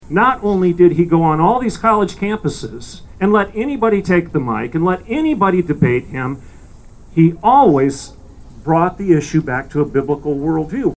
An estimated 300 people gathered outside of Bartlesville City Hall Tuesday night to remember the life and legacy of Charlie Kirk.
The vigil included hymns, prayer and messages from evangelical leaders from across the area.